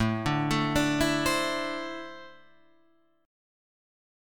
A4-3 Chord